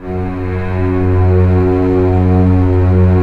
Index of /90_sSampleCDs/Roland L-CD702/VOL-1/STR_Cbs Arco/STR_Cbs2 Orchest